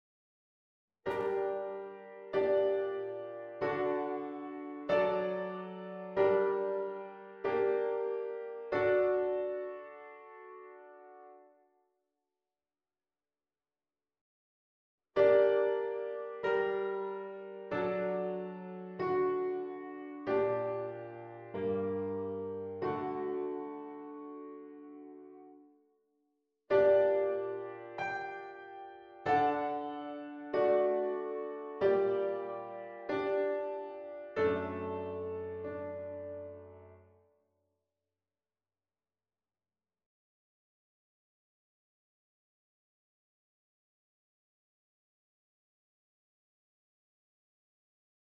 Vaak zal daarbij juist (ook) de sopraan naar een andere akkoordtoon springen.Zie voorbeeld 17 a en b. Ook 'binnen' een sextakkoord (dus:op een liggenblijvende bas) kan worden omgelegd, net als bij een grondligging.
liggingswisseling en omlegging